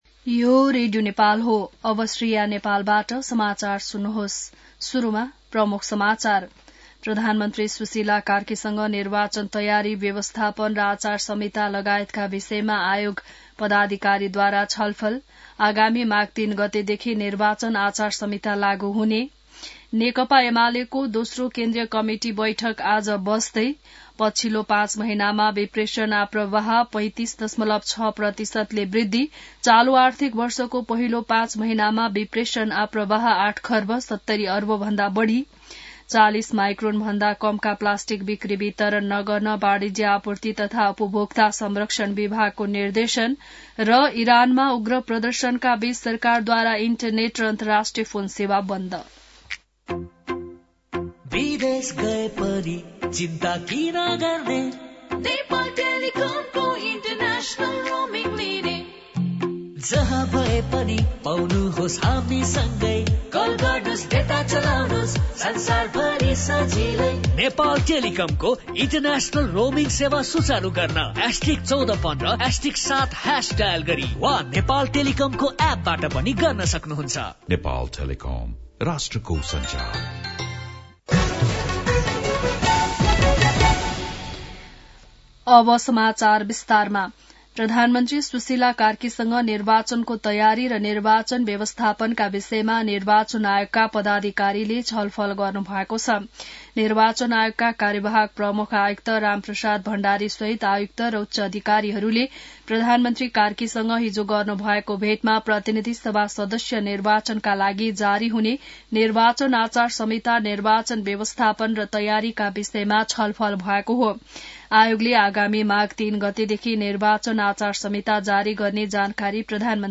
बिहान ७ बजेको नेपाली समाचार : २६ पुष , २०८२